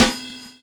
Snare (2).wav